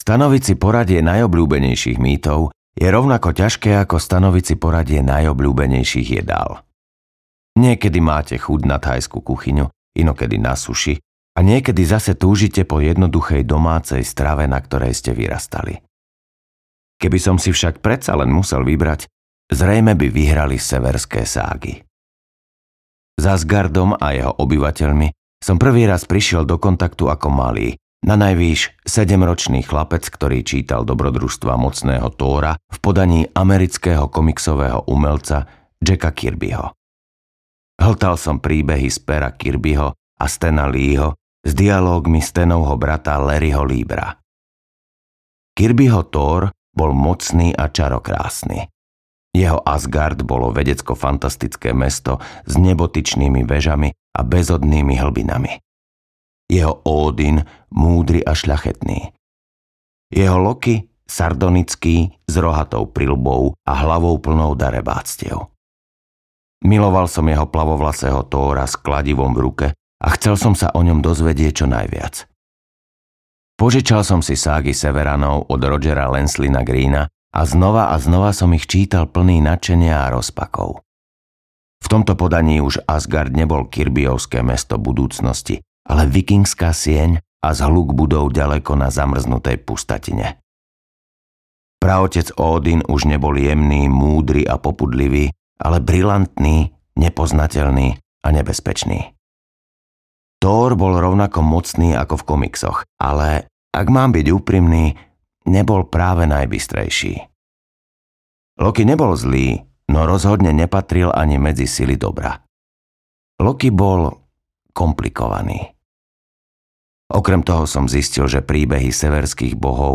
Severská mytológia audiokniha
Ukázka z knihy